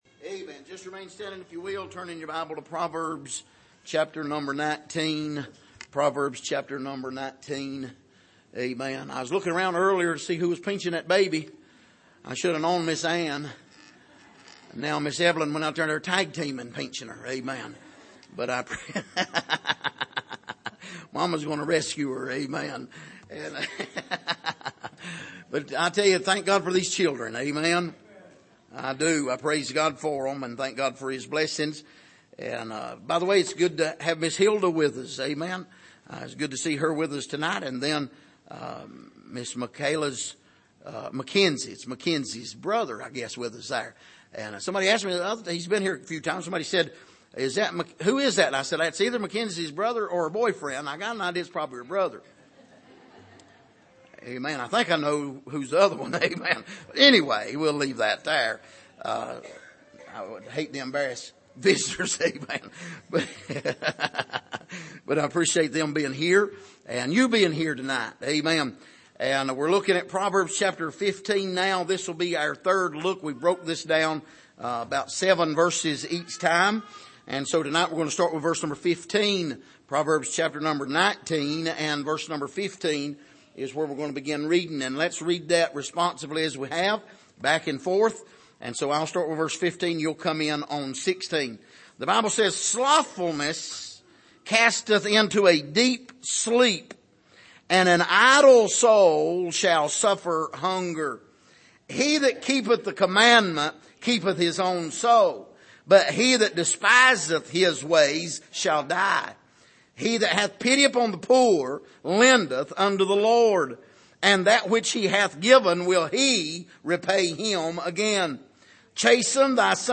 Passage: Proverbs 19:15-21 Service: Sunday Evening